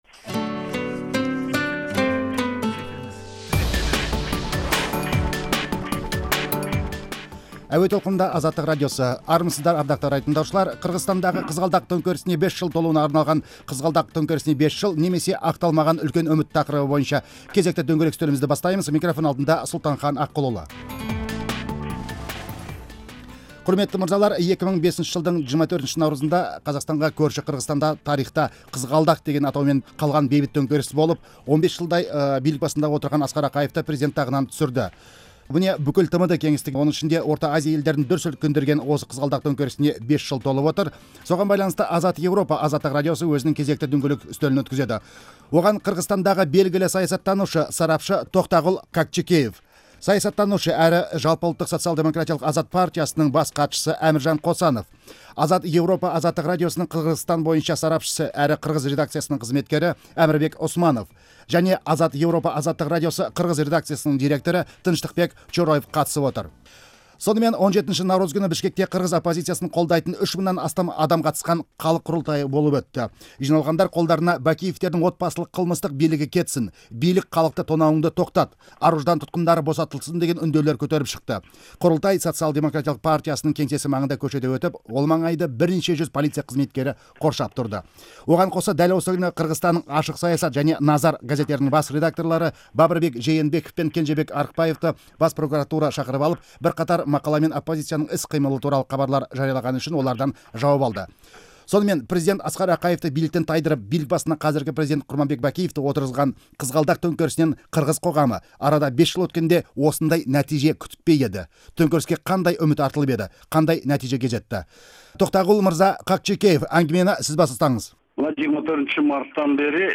Қырғызстан төңкерісінің 5 жылдығына арналған сұхбатты тыңдаңыз